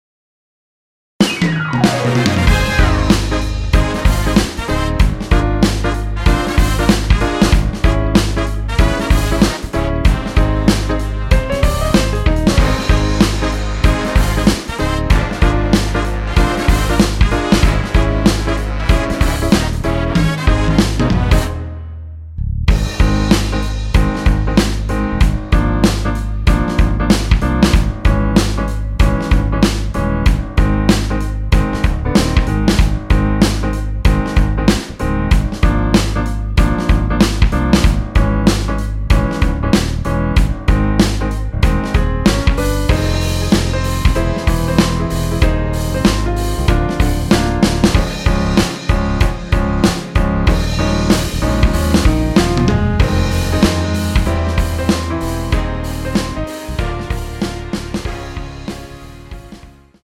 원키에서(-6)내린 MR입니다.
Ab
앞부분30초, 뒷부분30초씩 편집해서 올려 드리고 있습니다.
중간에 음이 끈어지고 다시 나오는 이유는